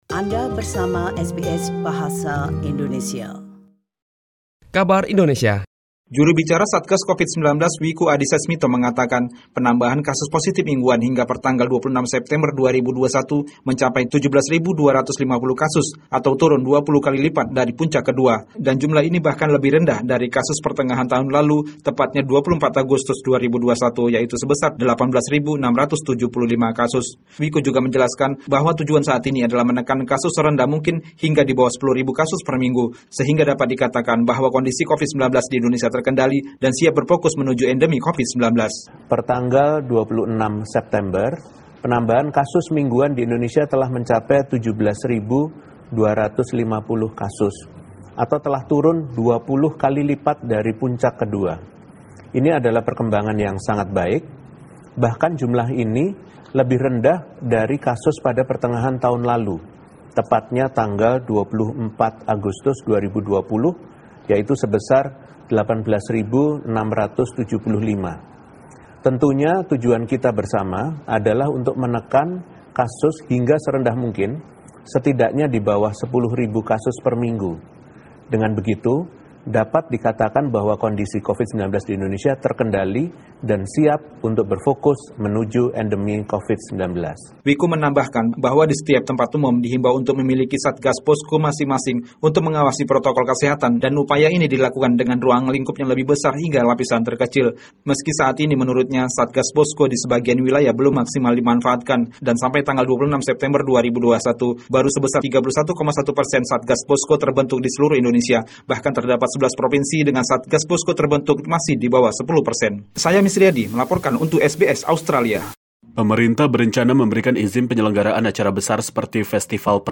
SBS Radio News in Bahasa Indonesia - 1 October 2021
Warta Berita Radio SBS Program Bahasa Indonesia Source: SBS